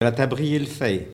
Notre-Dame-de-Monts
Locutions vernaculaires